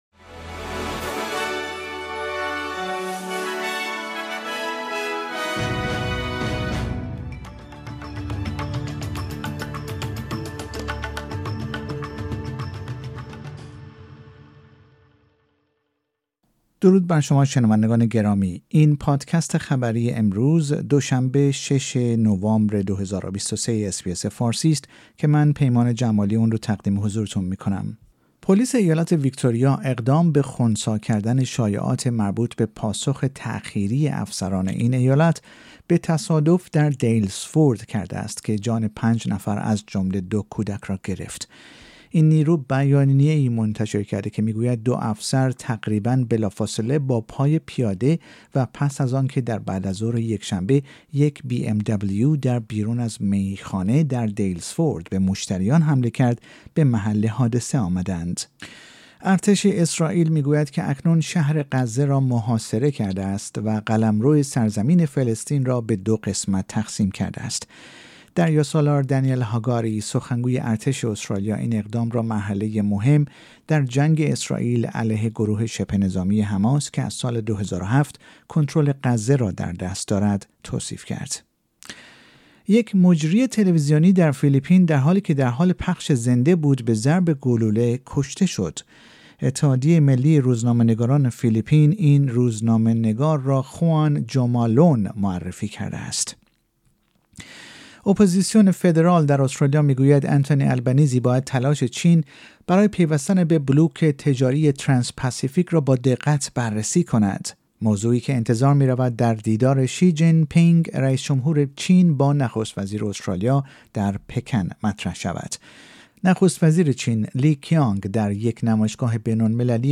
در این پادکست خبری مهمترین اخبار استرالیا و جهان در روز دو شنبه ششم نوامبر ۲۰۲۳ ارائه شده است.